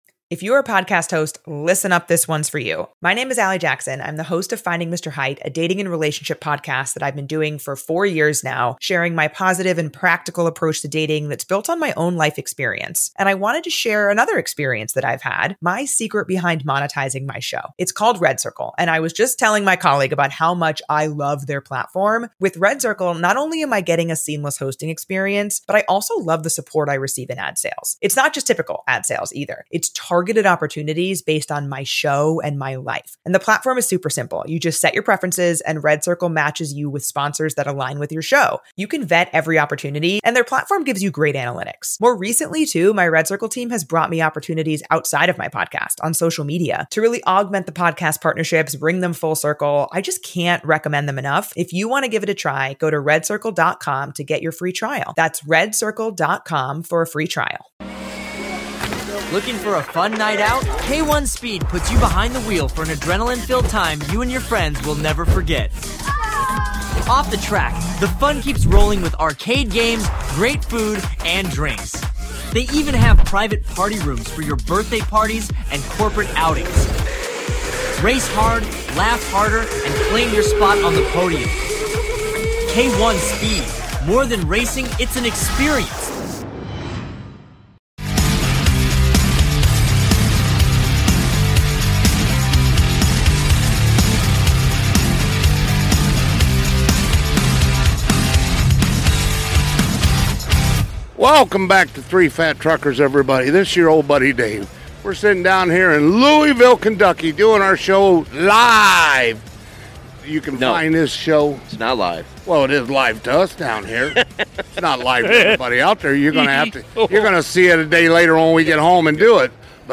We got to talk to a lot of great people, and just had a blast. We recorded this toward the end of the last day.